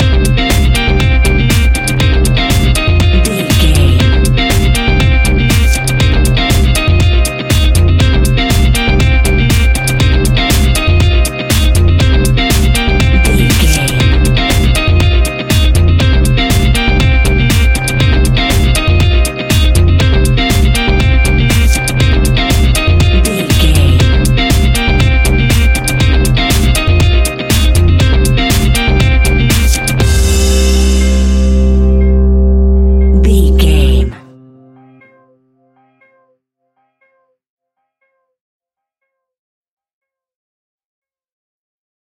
Aeolian/Minor
intense
energetic
repetitive
electric guitar
bass guitar
drums
synthesiser
drum machine
piano
funky house
electronic funk
upbeat
synth leads
Synth Pads
synth bass